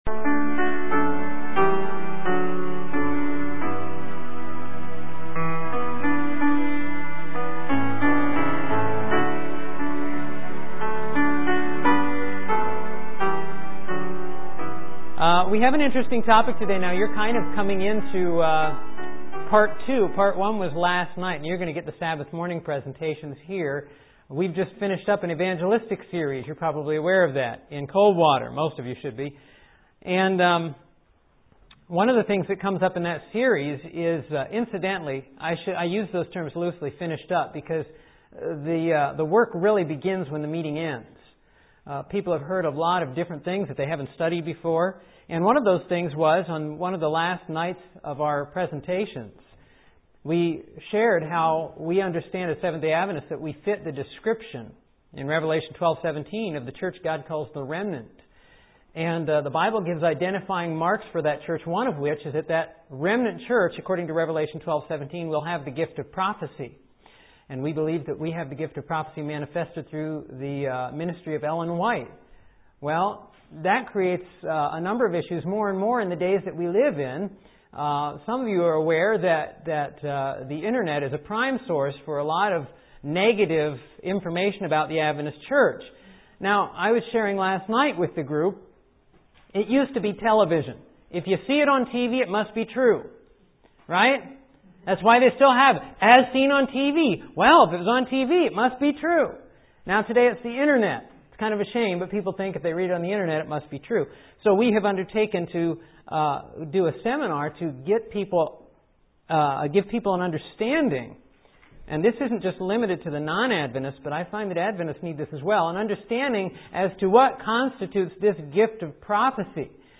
OVERVIEW A stirring message demonstrating how the Seventh-day Adventist Church truly is a movement of prophecy.